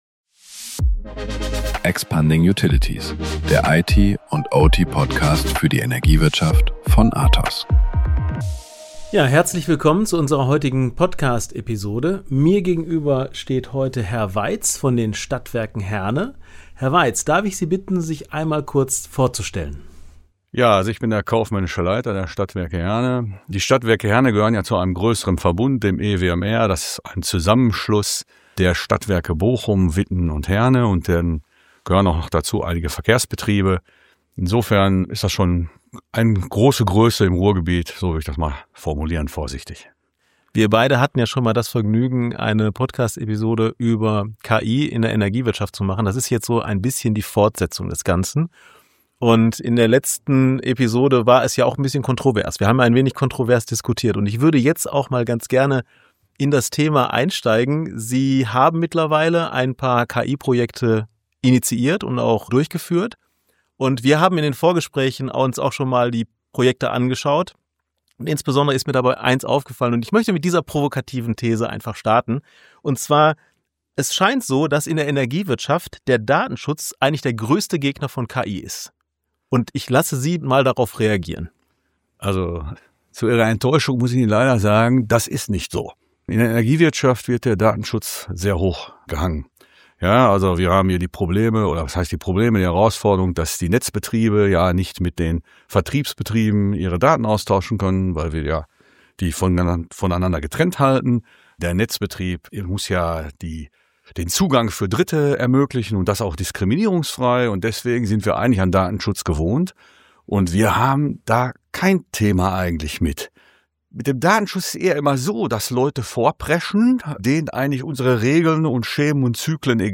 Freuen Sie sich auf einen offenen, praxisnahen Austausch zu einem der spannendsten Innovationsfelder der Energiebranche!